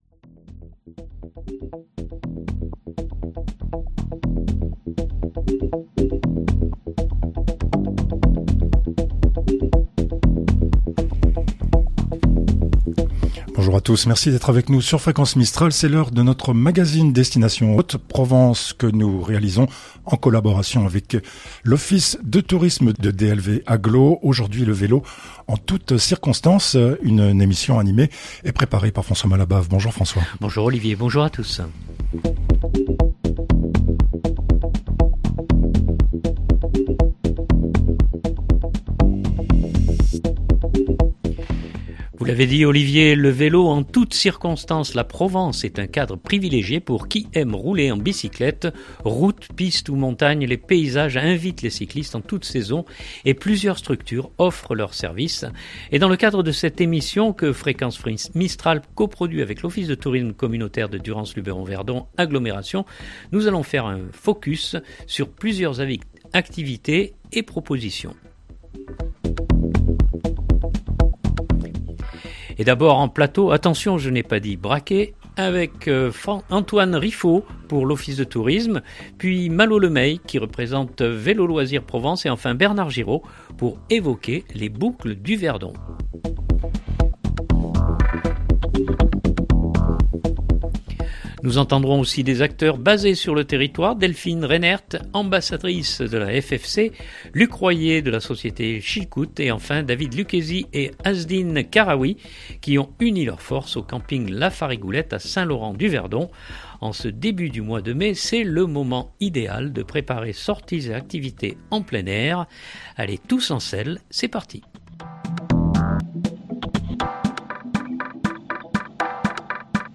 - Présentation, animation et reportage